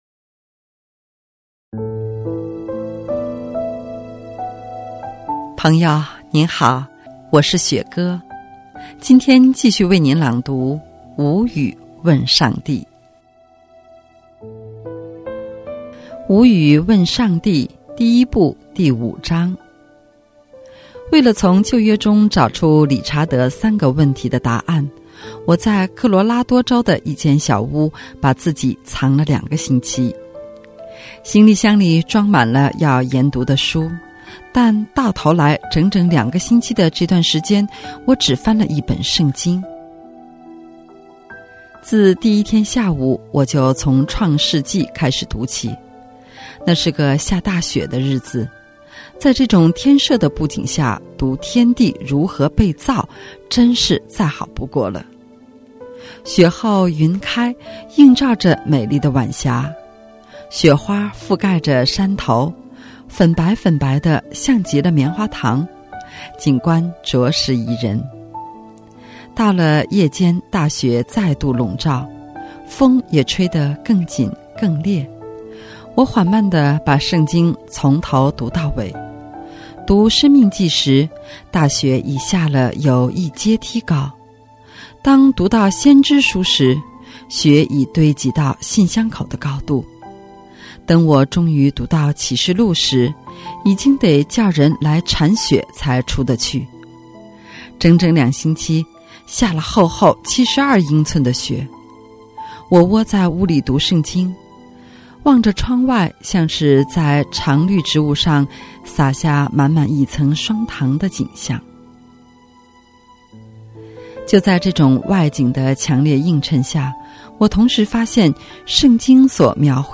首页 > 有声书 > 福音 > 无语问上帝 | 有声书 | 福音 > 无语问上帝 05：追根寻源